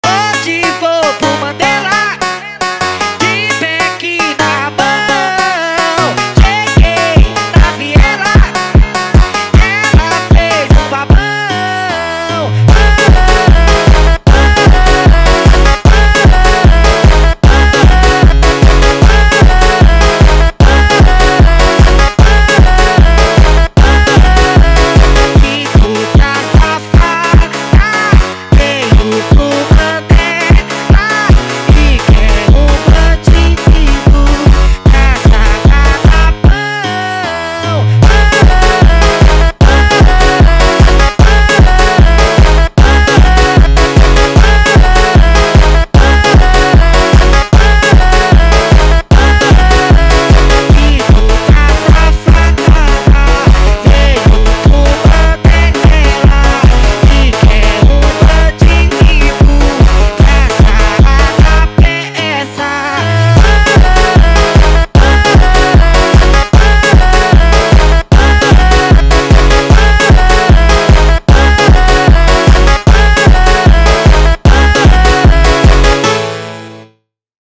فانکی با ریتمی سریع شده
فانک